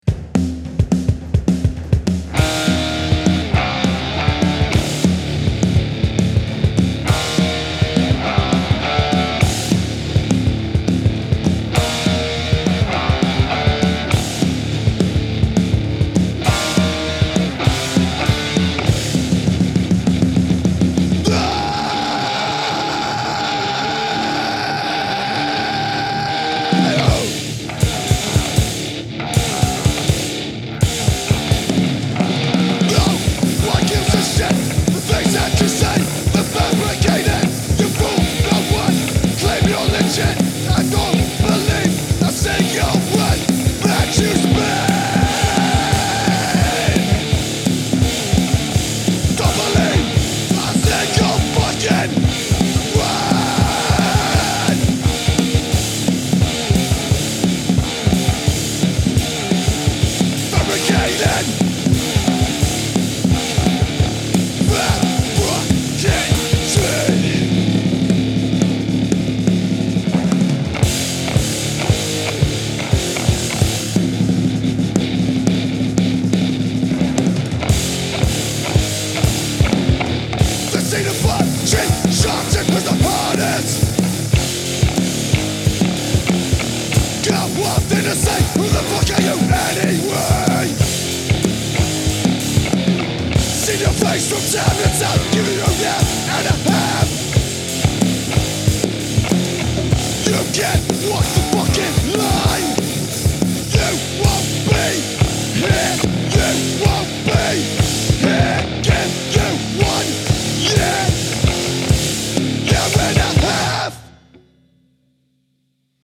Just Recorded first band independantly, could someone have a listen and give advice